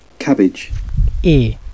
Pronunciation
Click over the word to hear both the word and the phonetic sound pronounced.
cabbageuk.wav